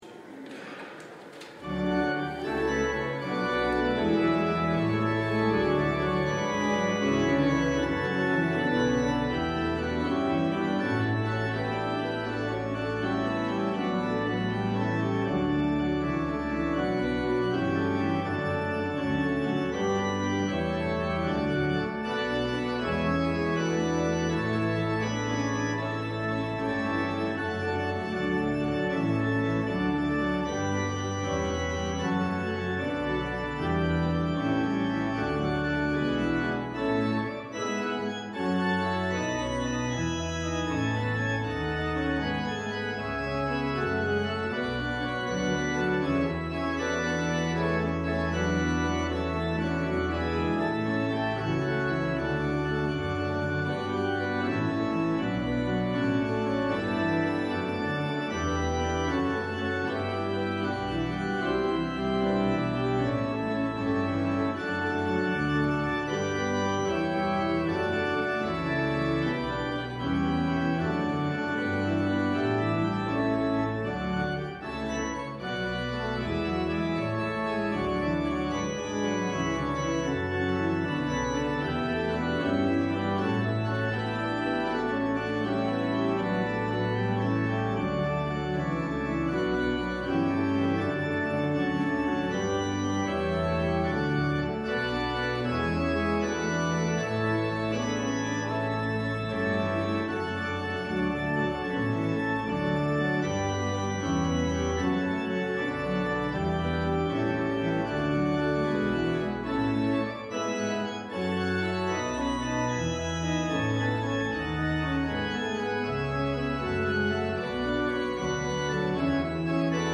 LIVE Midday Worship Service - The Image of the Invisible God: Sovereignty
Congregational singing—of both traditional hymns and newer ones—is typically supported by our pipe organ.